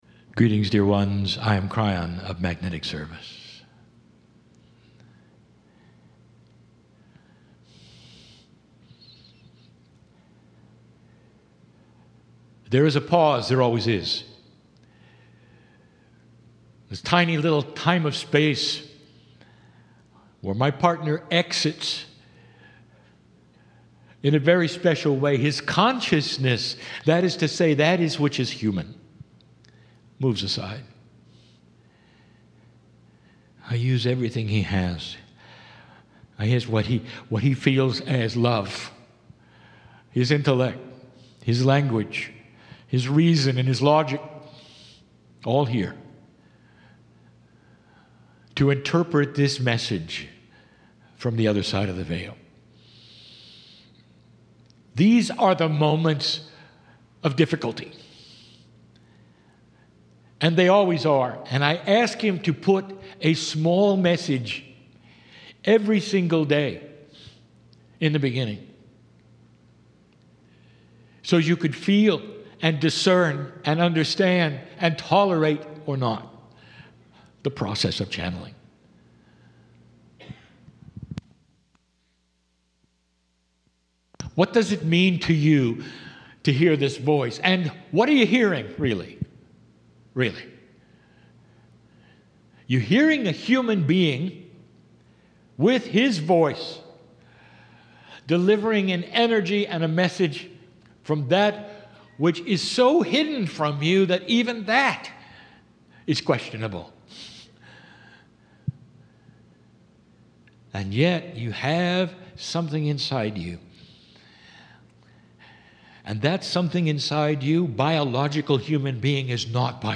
"Mini Channelling"